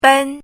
chinese-voice - 汉字语音库
ben1.mp3